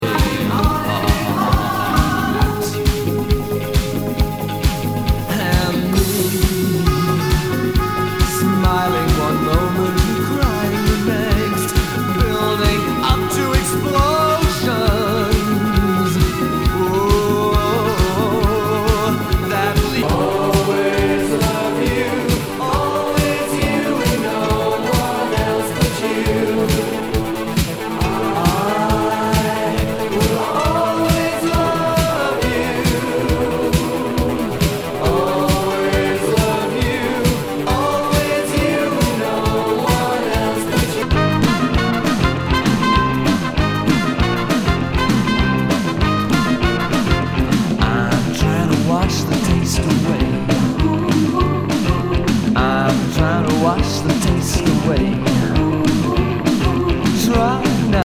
ROCK/POPS/INDIE
ナイス！シンセ・ポップ！
全体にチリノイズが入ります